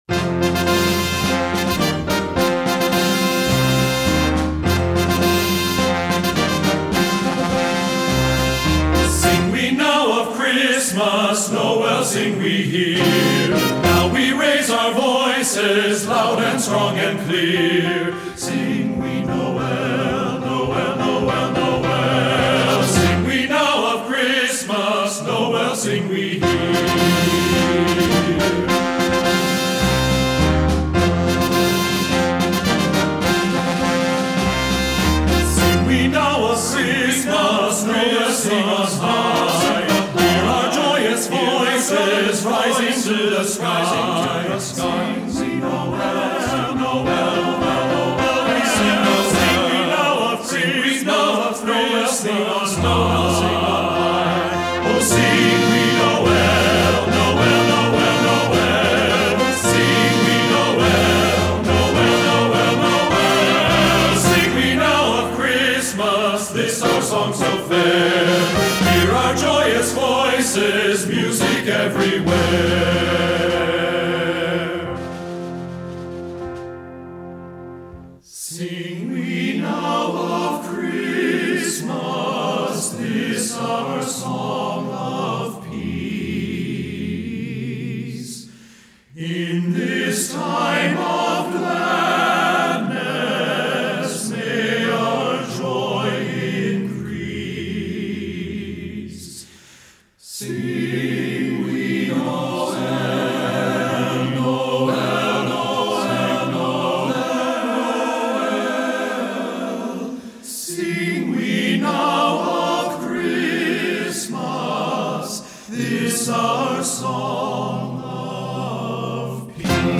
Composer: French Carol
Voicing: TTBB